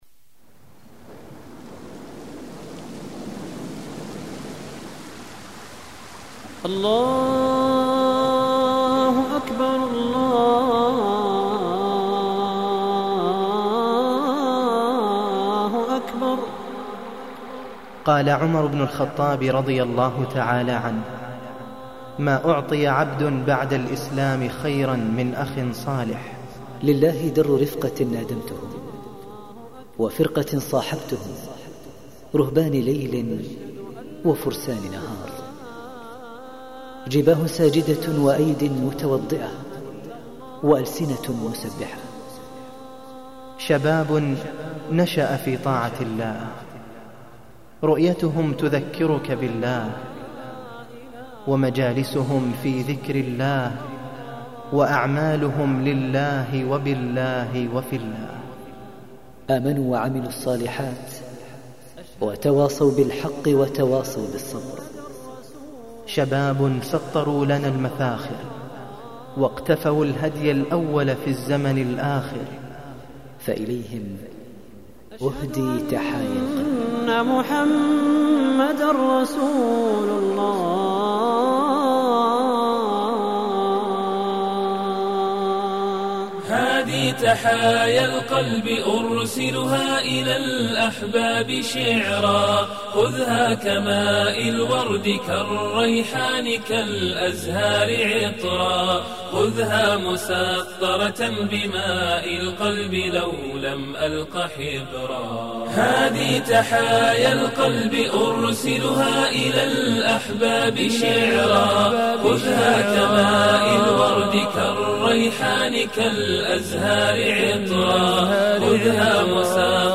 أناشيد ونغمات